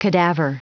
Prononciation du mot cadaver en anglais (fichier audio)
Prononciation du mot : cadaver